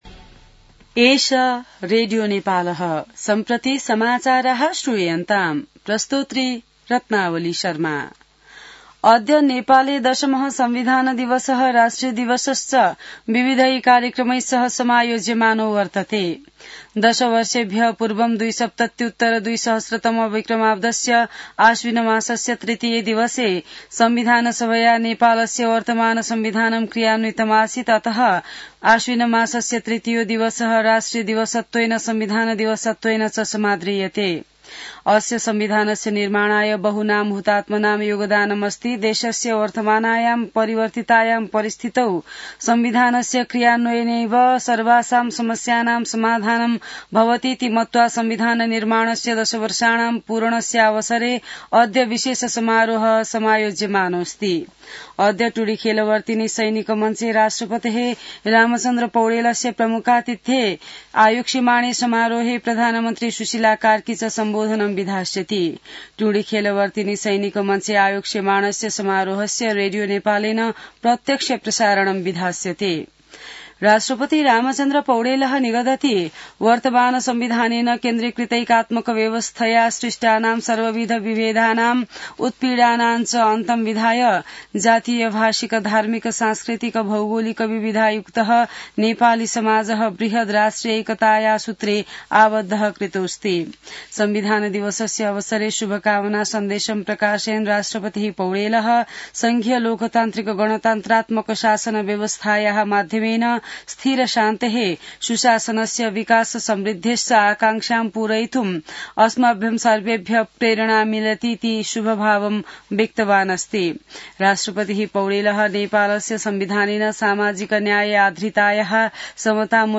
An online outlet of Nepal's national radio broadcaster
संस्कृत समाचार : ३ असोज , २०८२